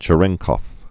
(chə-rĕngkôf, -kəf) also Če·ren·kov effect (chə-rĕngkôf, -kəf)